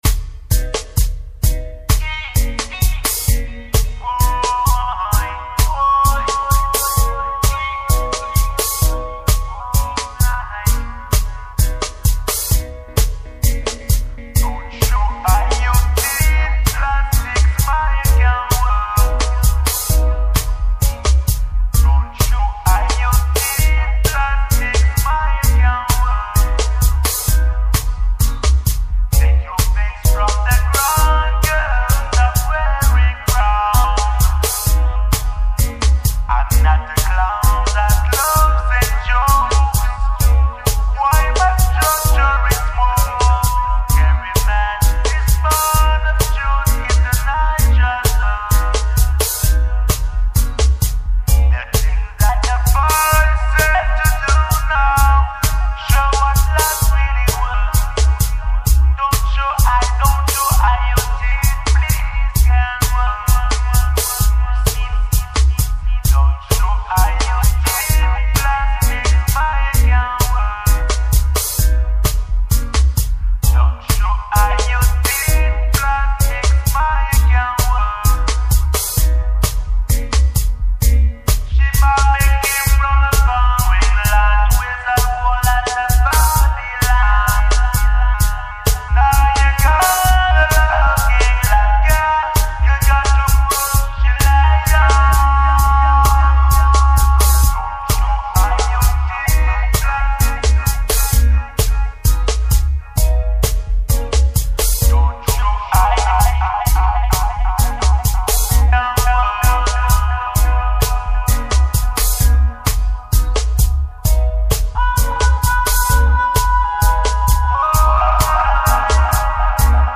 Vocal & Riddim